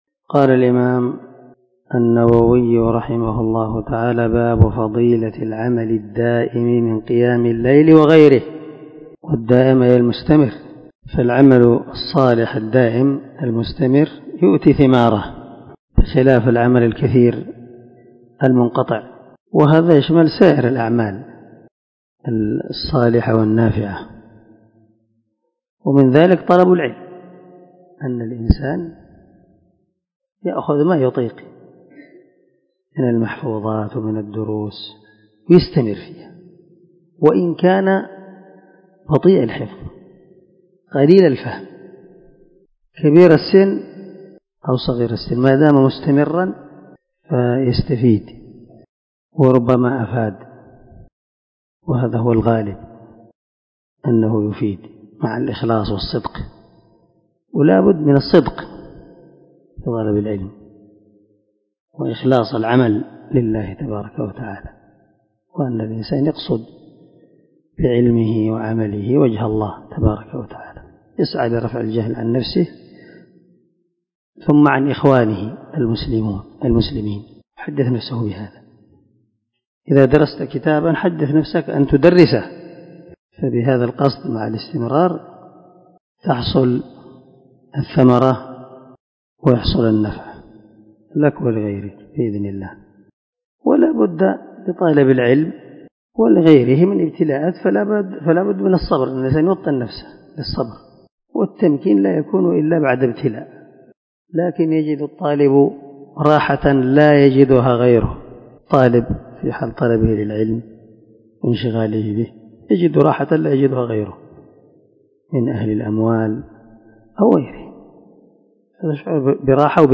474الدرس 42 من شرح كتاب صلاة المسافر وقصرها حديث رقم ( 782 – 787 ) من صحيح مسلم